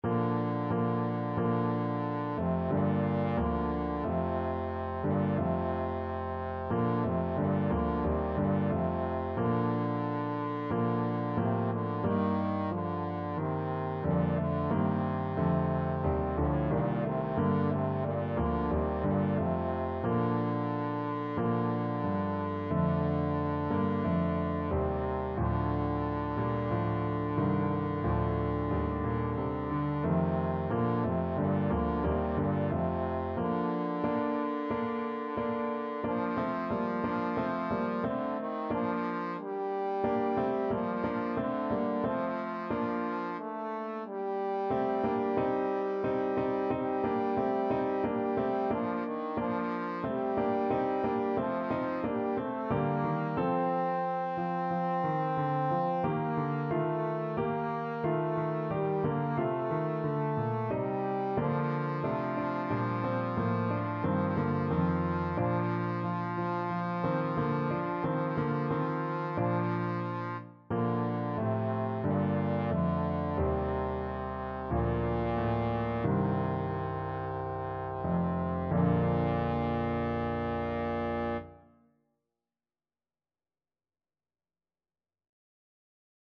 Classical Rachmaninoff, Sergei Bogoroditse Devo from the Vespers Trombone version
Trombone
Bb major (Sounding Pitch) (View more Bb major Music for Trombone )
4/4 (View more 4/4 Music)
A3-C5
Classical (View more Classical Trombone Music)